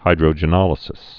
(hīdrō-jə-nŏlĭ-sĭs)